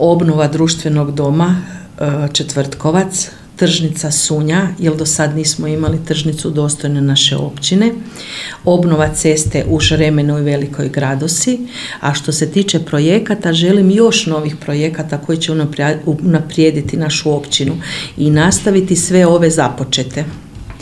U našoj emisiji Srijedište načelnica Crnko izdvojila je još neke projekte